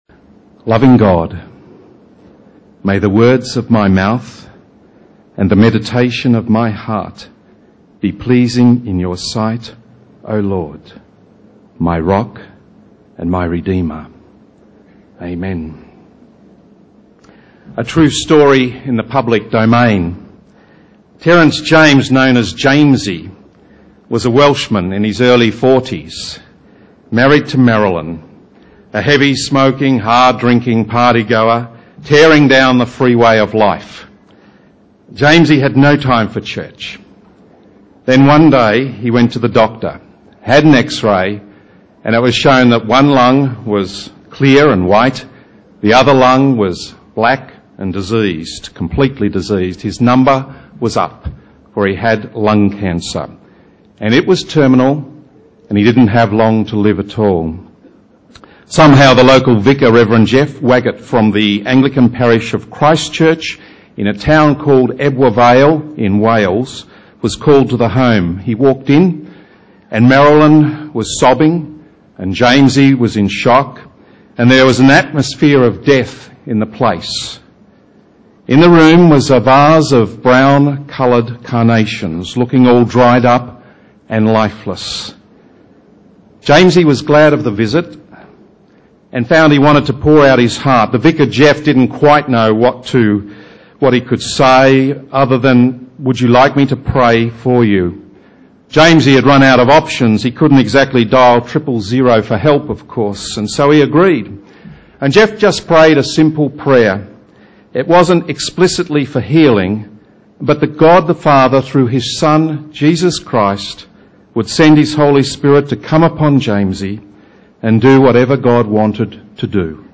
Sermons | St Alfred's Anglican Church
In this sermon